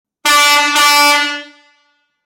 Alarme Sonoro Industrial Modelo 3
Tem como característica o som de baixa frequência e o longo alcance além de atender as exigências legais das áreas de segurança do trabalho e/ou das brigadas de incêndio das empresas.
• 01 Corneta em plástico de engenharia;
• Intensidade sonora 150db;
• Som de baixa frequência e longo alcance;
• Sistema pneumático;